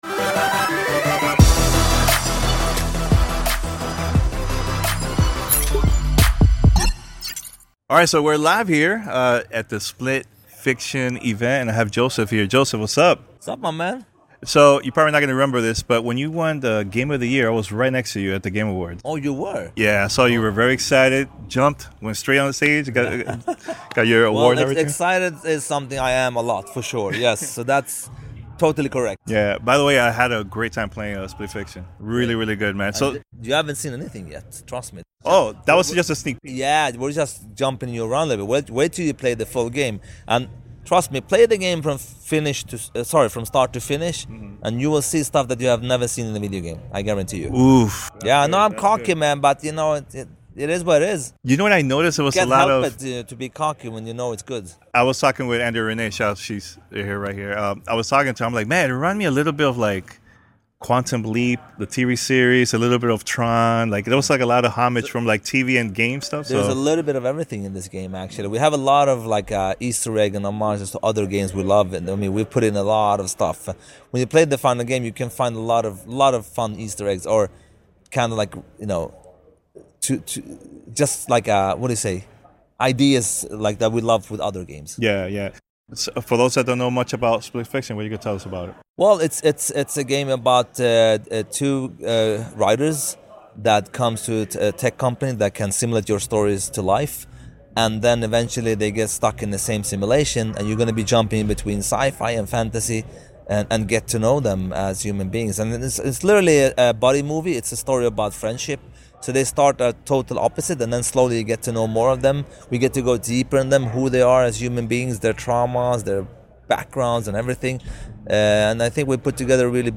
Split Fiction Interview with Josef Fares
Interview with Josef Fares, founder of Hazelight Studios, about Split Fiction.